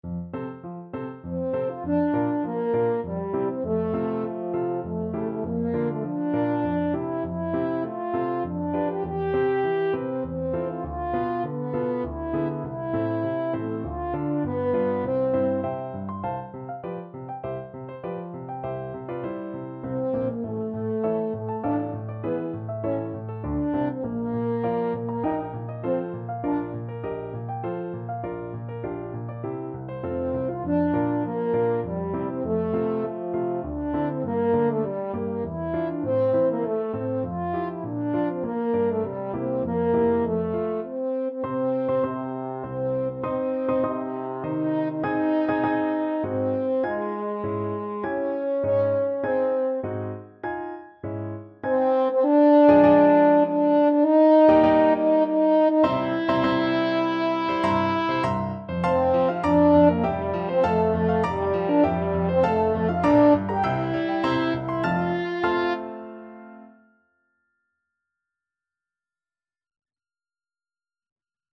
French Horn
F major (Sounding Pitch) C major (French Horn in F) (View more F major Music for French Horn )
~ = 100 Tempo di Menuetto
3/4 (View more 3/4 Music)
Classical (View more Classical French Horn Music)